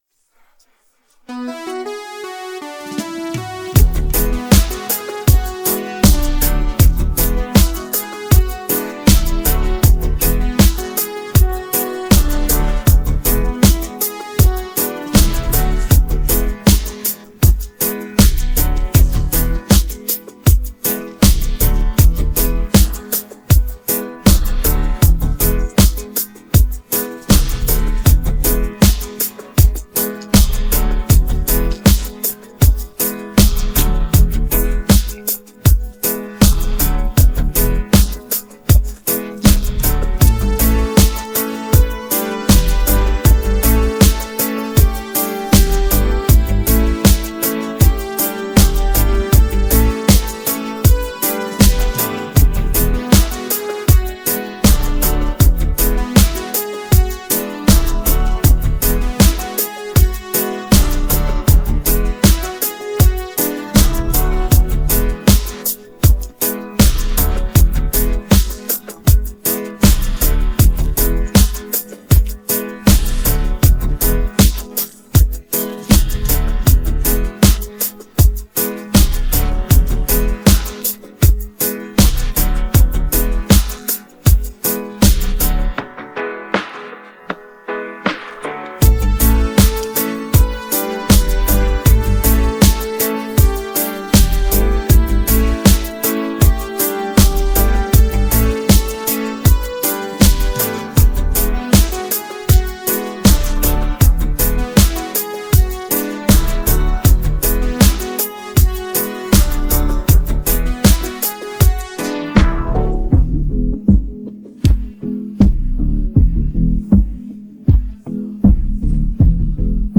DancehallReggae
characterized by its smooth beats and infectious hooks.